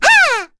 kingsraid-audio / voices / heroes / en / Rehartna-Vox_Landing_b.wav
Rehartna-Vox_Landing_b.wav